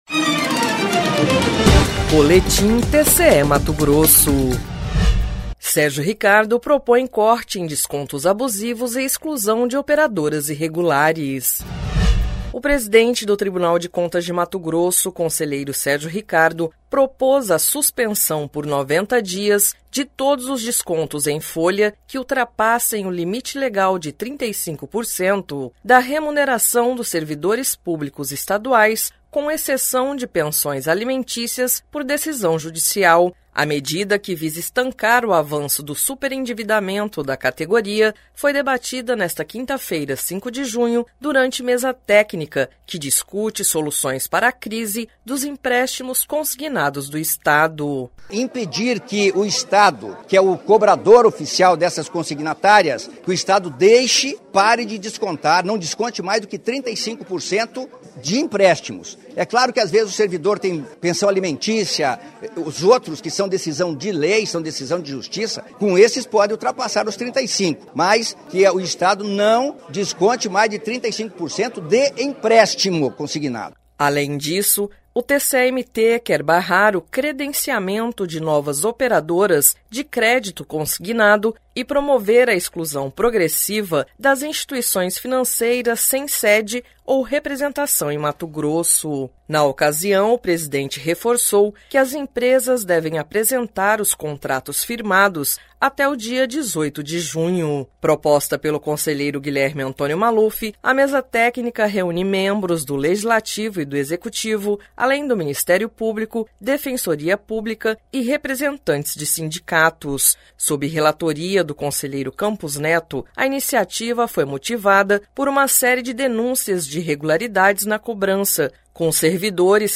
Sonora: Sérgio Ricardo – conselheiro presidente do TCE-MT
Sonora: Guilherme Antonio Maluf – conselheiro vice-presidente do TCE-MT
Sonora: Wilson Santos – deputado por MT